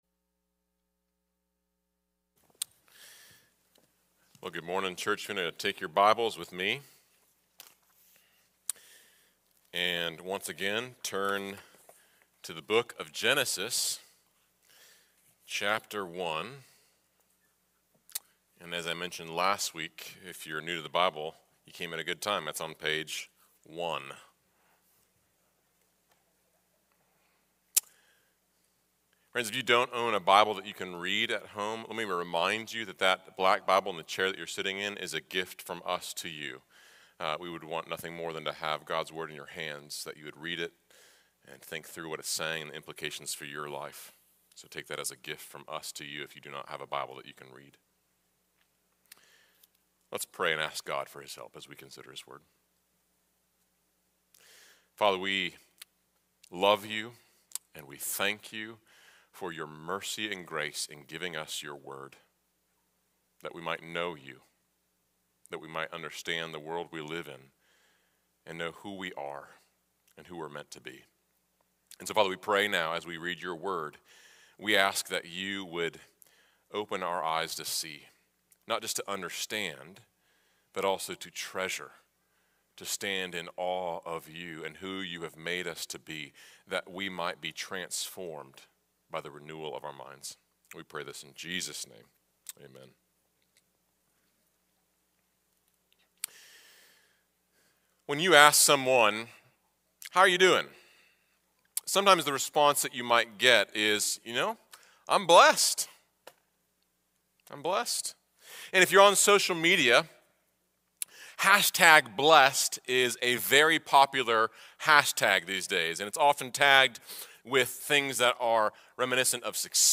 FBC Sermons